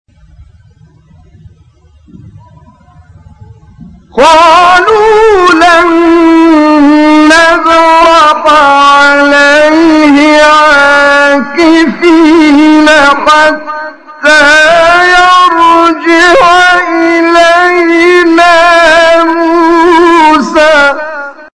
9 فراز از «کامل یوسف» در مقام صبا
به گزارش خبرگزاری بین‌‌المللی قرآن(ایکنا) 9 مقطع صوتی از کامل یوسف البهتیمی، قاری برجسته مصری که در مقام صبا اجرا شده، در کانال تلگرامی کامل یوسف البهتیمی منتشر شده است، در زیر ارائه می‌شود.
برچسب ها: خبرگزاری قرآن ، ایکنا ، شبکه اجتماعی ، کامل یوسف البهتیمی ، قاری مصری ، مقام صبا ، فراز صبا ، تلاوت قرآن ، مقطعی از تلاوت ، قرآن ، iqna